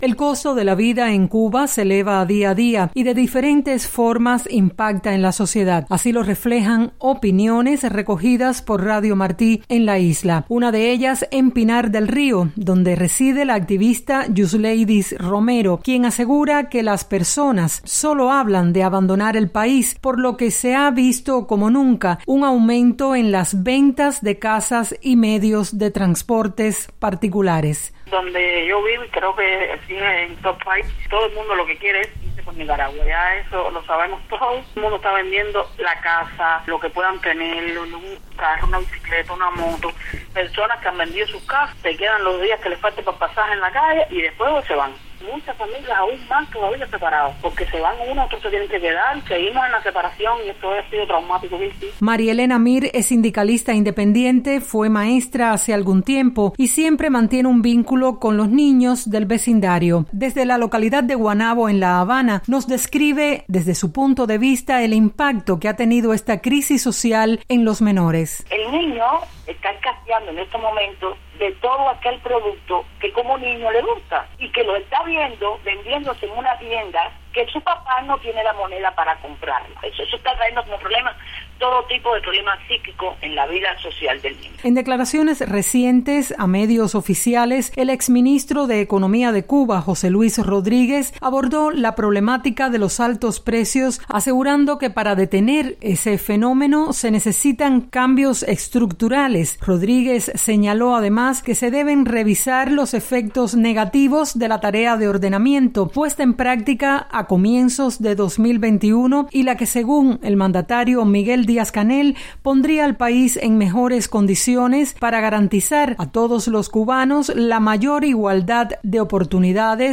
Reporte